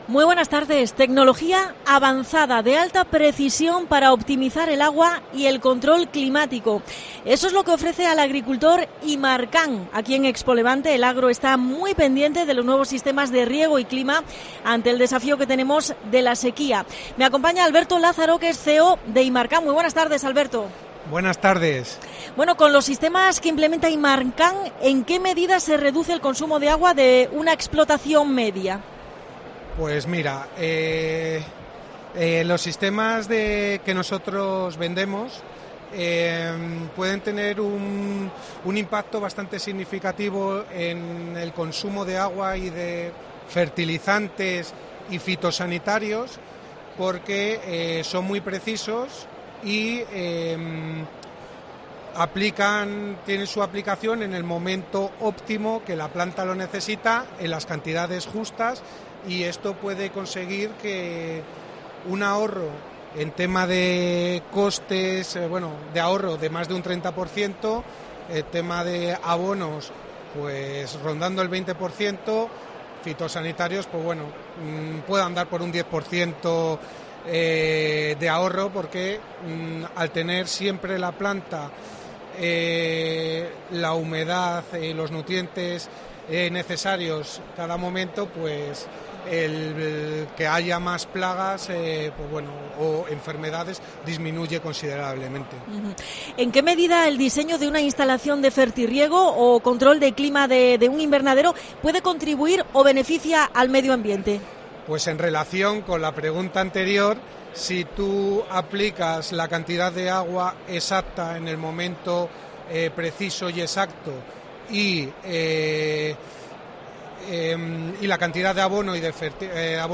Especial ExpoLevante 2024 en Cope Almería. Entrevista